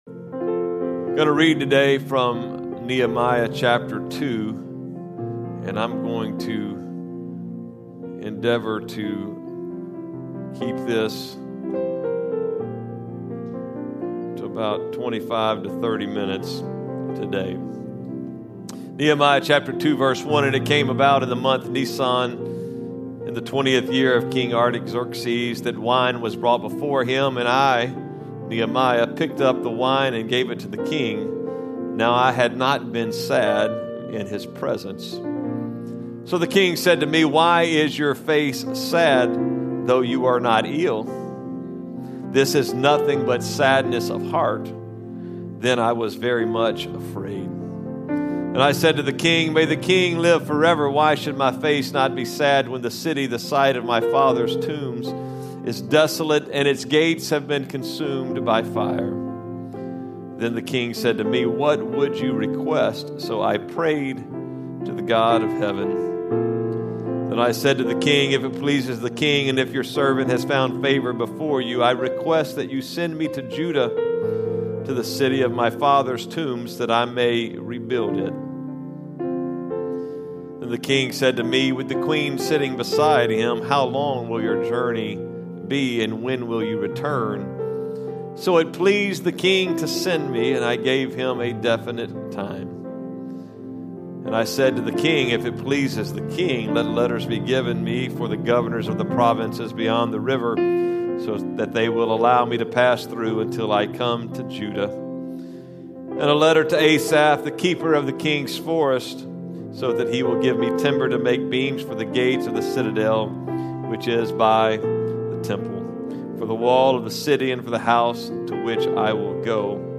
Sermons | Cross Church Kansas City